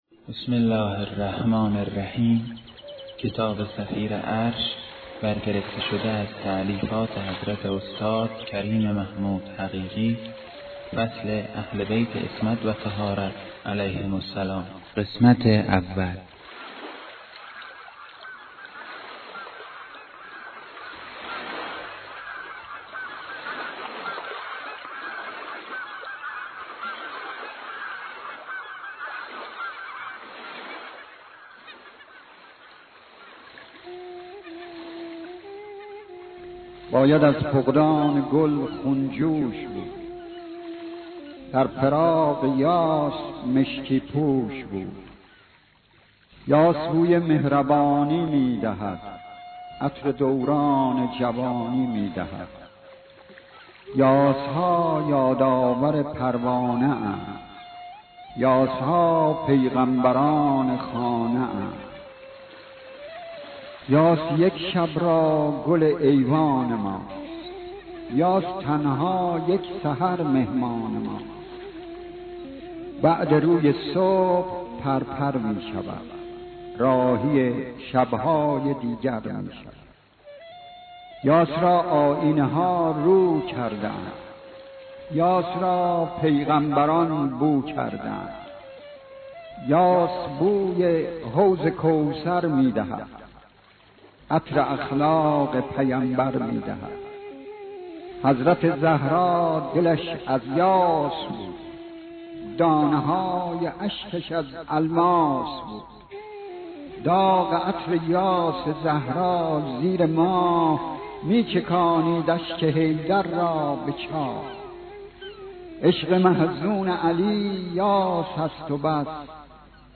فصل اهل بیت عصمت و طهارت علیهم السلام در دو قسمت، جرعه هایی زلال از معارف الهی همراه با نغمه هایی بسیار زیبا از طبیعت
کتاب صوتی صفیر عرش، اهل بیت عصمت و طهارت قسمت اول